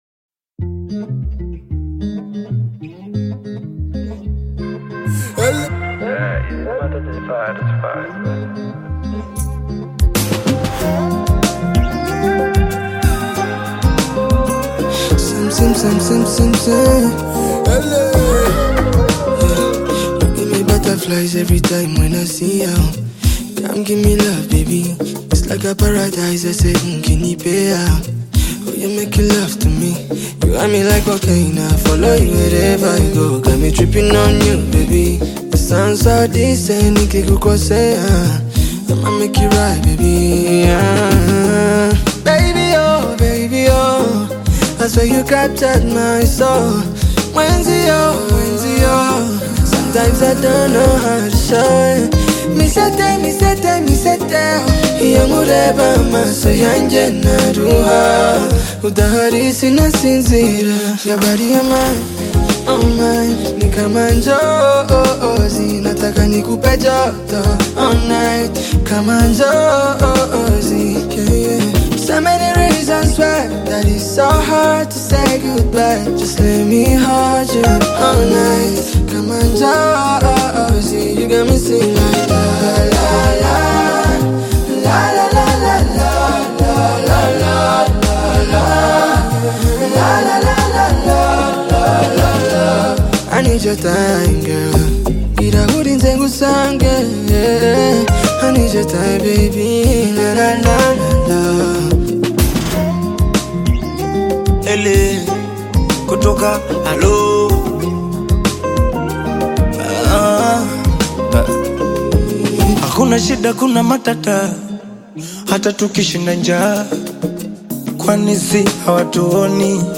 Bongo Flava music track